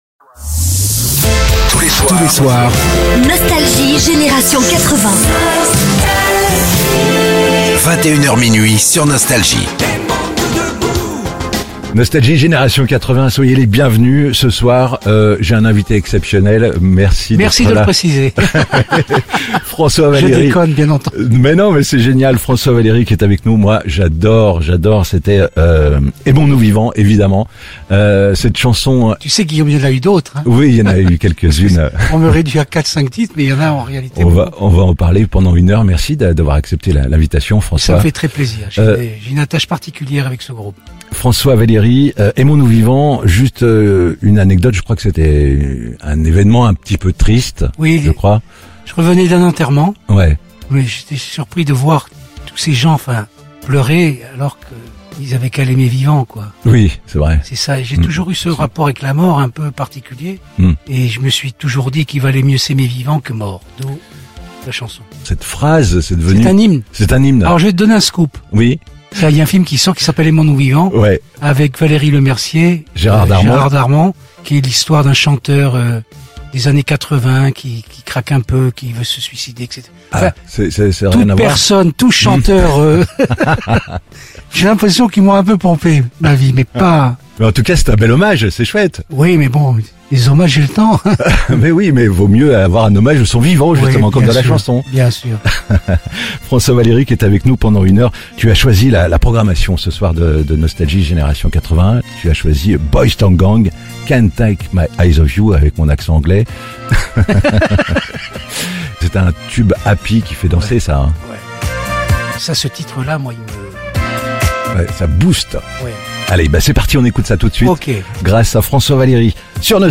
On connait tous un grand tube de François Valéry, Invité exceptionnel de Nostalgie à l’occasion de la sortie de son album "le Répertoire", l'artiste a fait la programmation de Nostalgie et partagé des anecdotes, dont une très touchante sur Tina Turner.